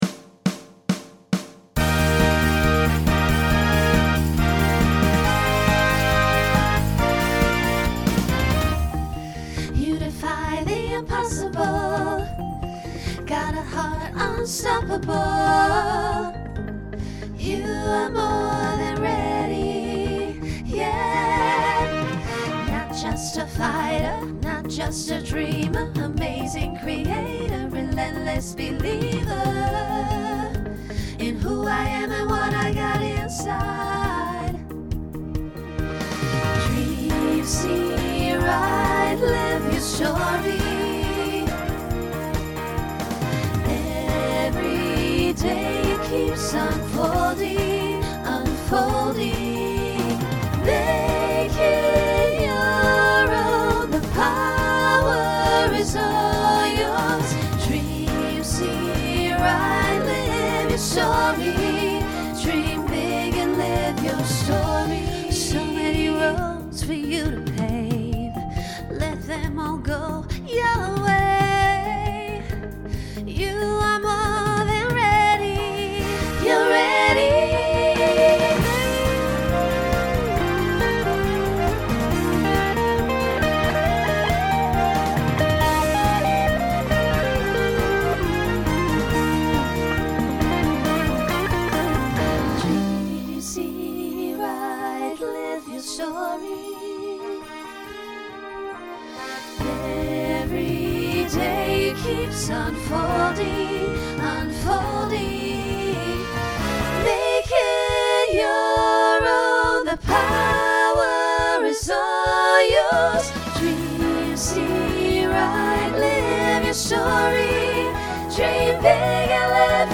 Genre Pop/Dance Instrumental combo
Transition Voicing SSA